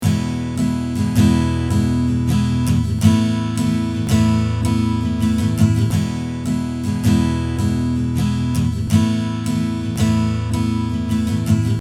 Here is a sample of Stereo Strumming - mono then stereo
(Microphones placed near the 2 separate sound-holes .... 38mm pick ... panned left/right)
(Note: No reverb EQ etc.)
Strumming mono - stereo.mp3